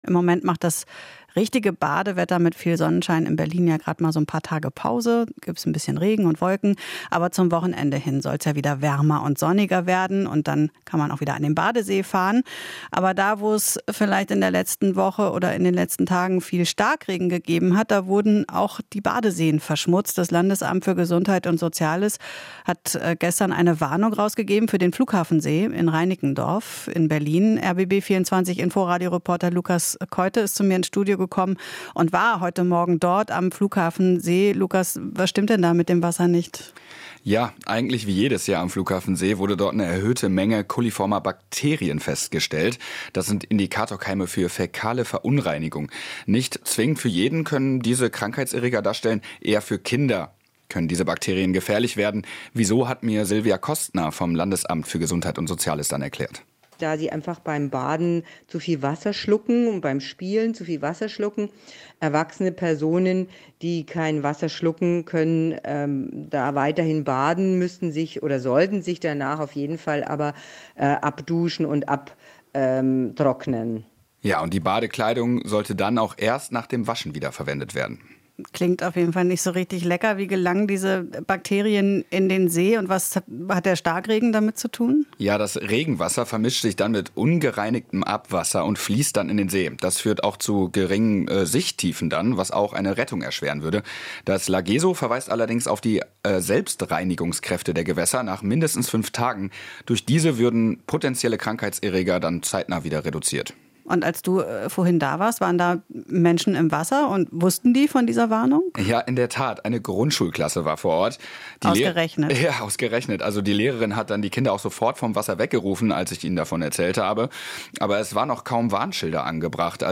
Interview - Kein Baden im Flughafensee - Wasserqualität sonst meistens gut